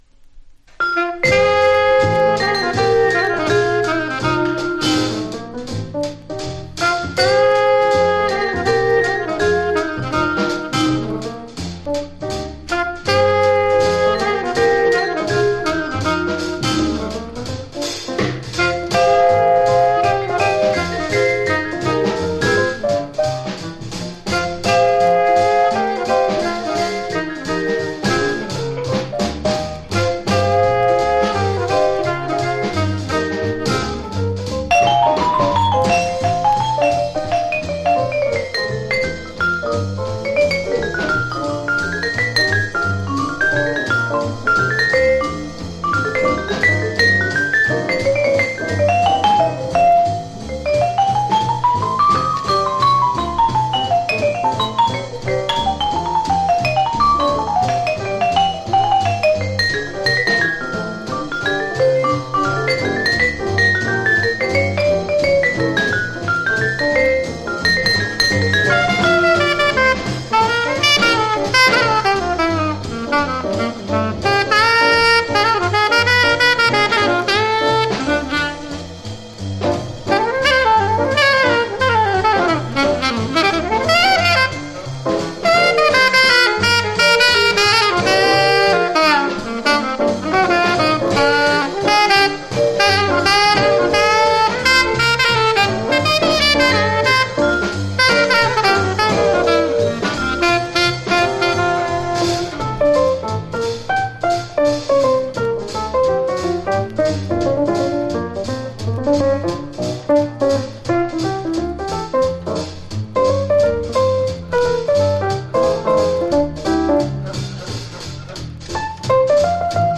Vibe